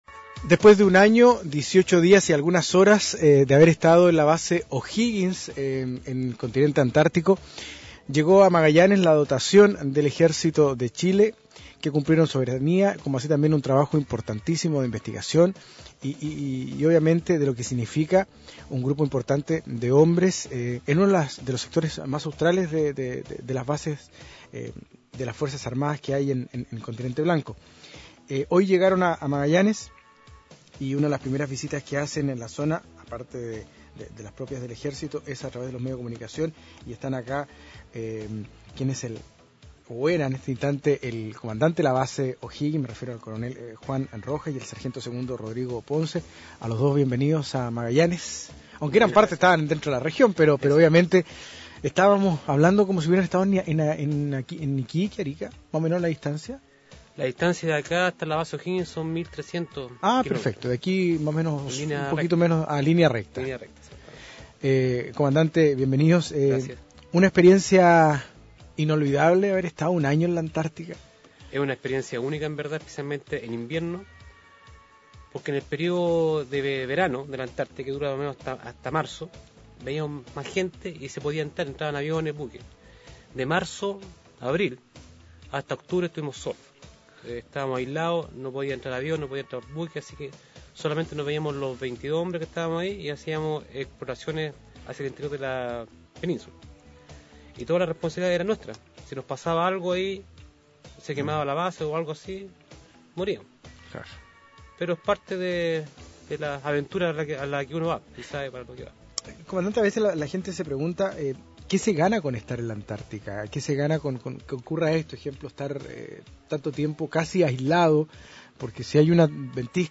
Entrevistas de Pingüino Radio - Diario El Pingüino - Punta Arenas, Chile
Vladimiro Mimica, alcalde